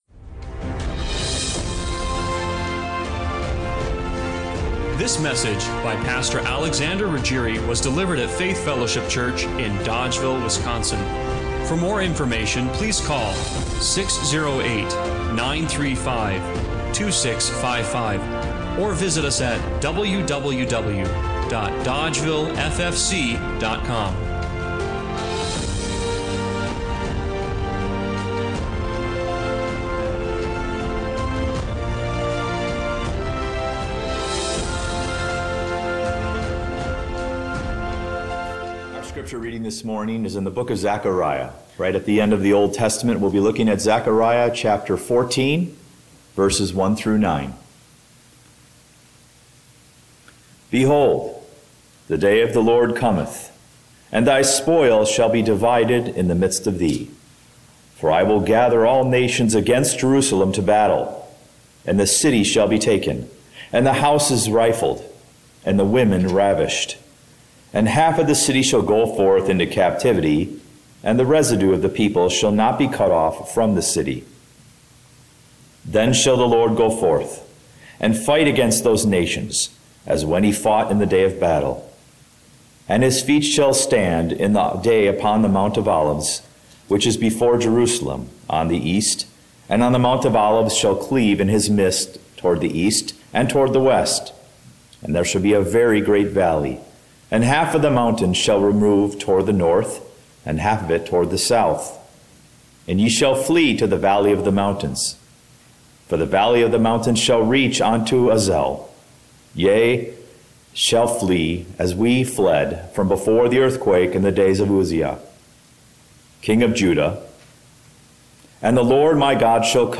Acts 1:9-14 Service Type: Sunday Morning Worship What do you do when Jesus feels far away?